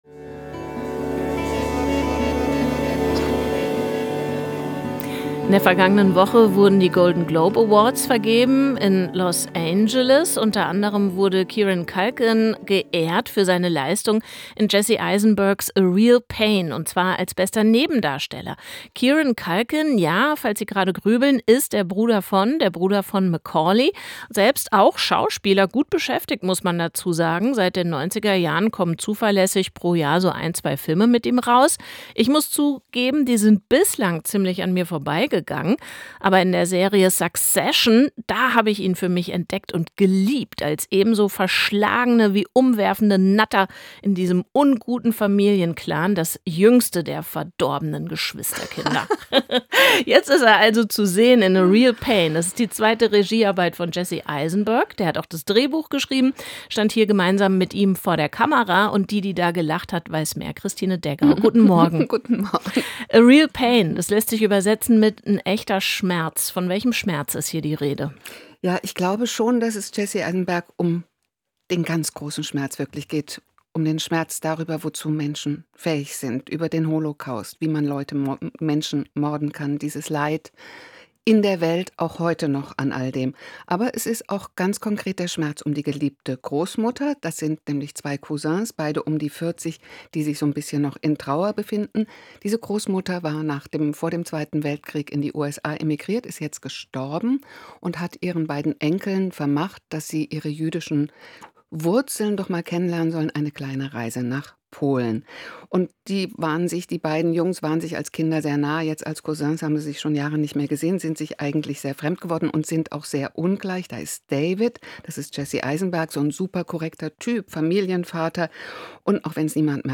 Filmkritik: "A Real Pain" von Jesse Eisenberg 6:04